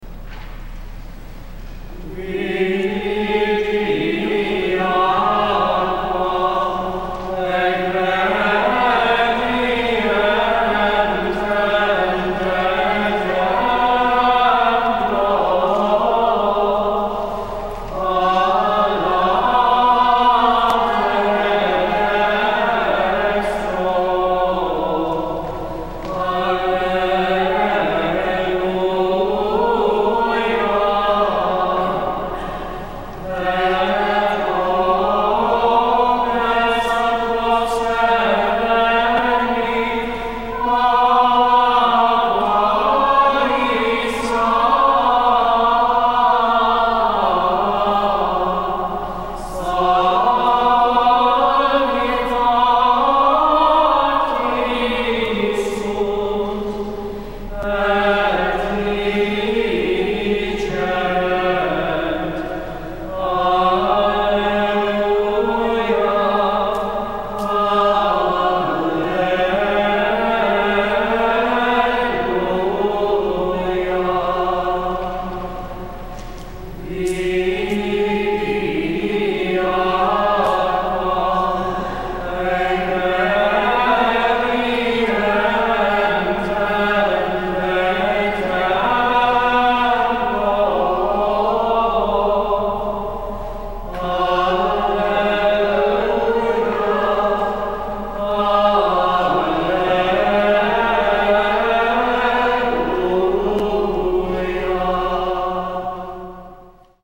Here are audio clips of some Gregorian chant (excerpts in each case) from the abbey, last Sunday’s Fifth Sunday of Easter, with some musings.
Vidi_aquam is the chant during sprinkling in Easter season.
Especially when it’s such as delightful piece as this, with its flowing melismas that almost sound like flowing water.
So I signalled to sing the first line plus the final alleluia.